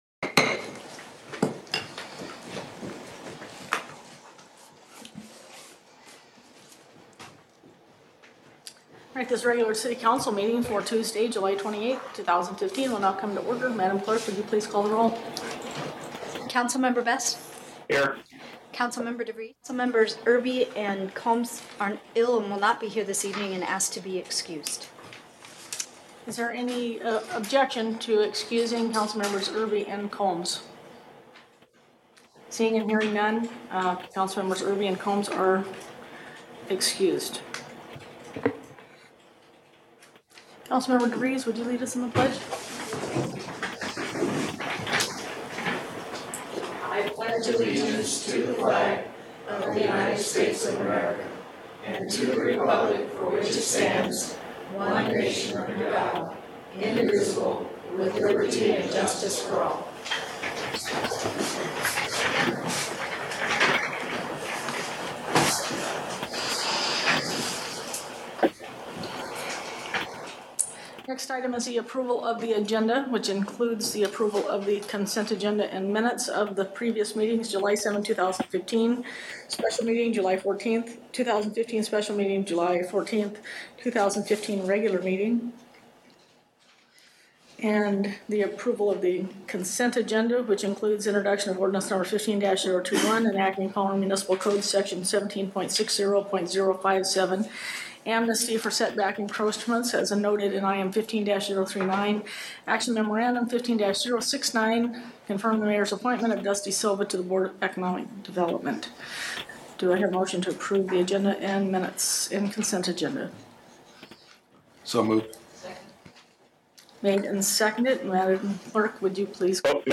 City of Palmer Council Meeting 7.28.15